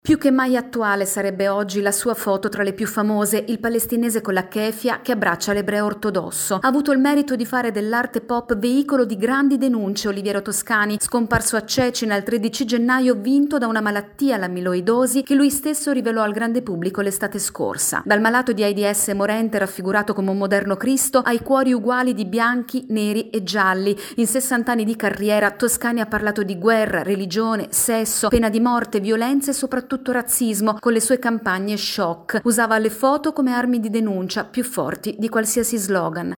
È scomparso a 82 anni il fotografo noto per le sue campagne choc e per aver portato nella pop art la foto come senso critico. Il servizio